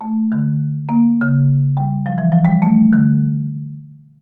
Famille : percussions
Il a deux rangées de lames en bois, sur lesquelles on frappe avec des mailloches.
Marimba